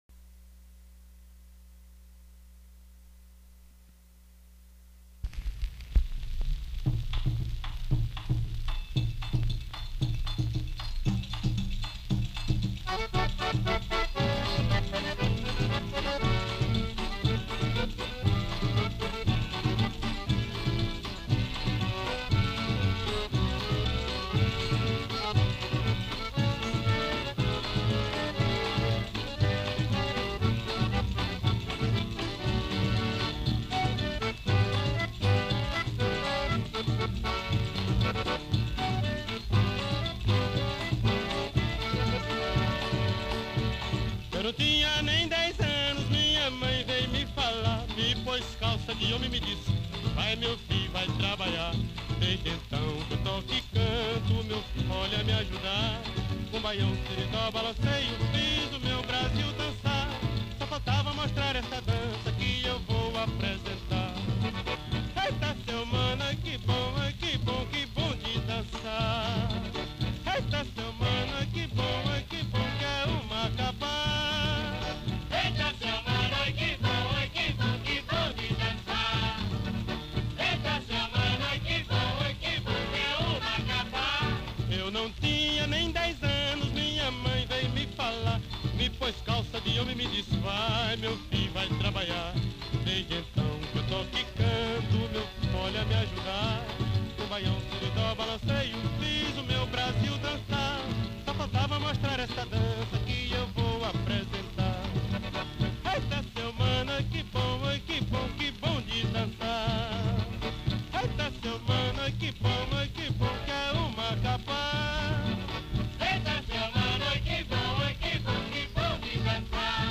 DISCO 78RPM